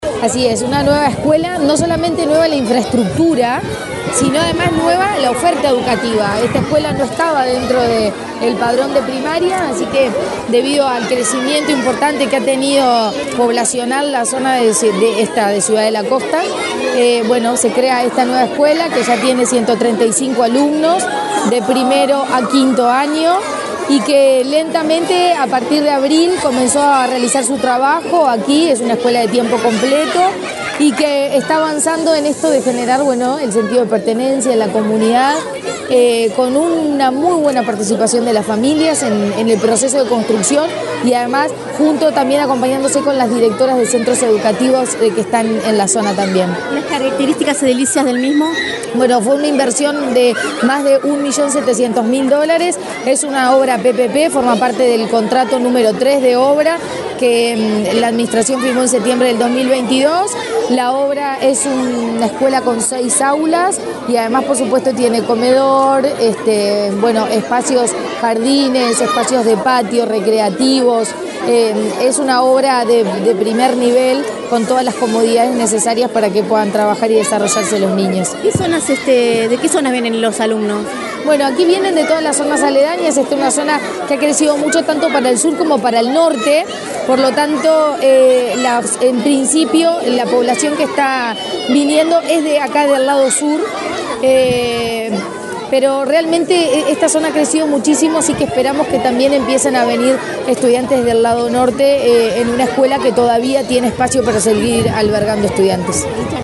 Declaraciones de la presidenta de la ANEP, Virginia Cáceres
Declaraciones de la presidenta de la ANEP, Virginia Cáceres 18/10/2024 Compartir Facebook X Copiar enlace WhatsApp LinkedIn La presidenta de la Administración Nacional de Educación Pública (ANEP), Virginia Cáceres, dialogó con la prensa, antes de participar en el acto de inauguración de escuela n.° 321 de la zona de Pinepark, en la Costa de Oro de Canelones.